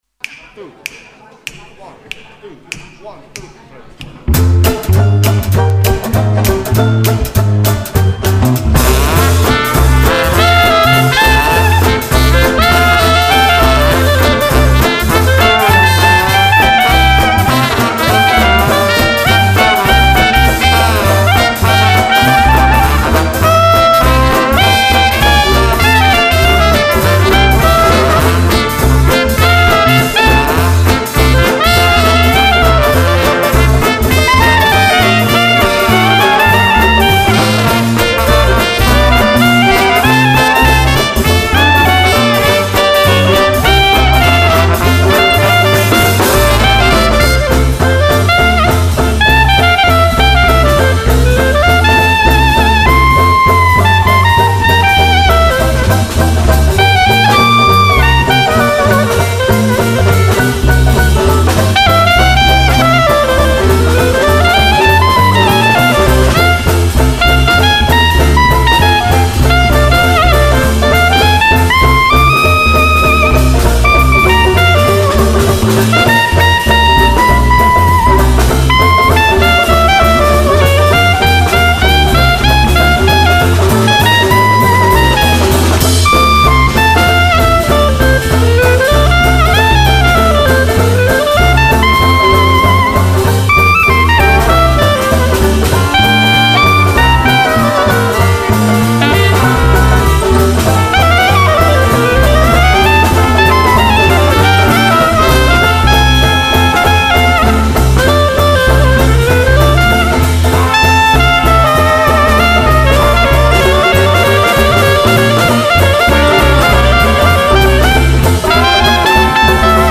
Dixie Family | LIVE in Dortmund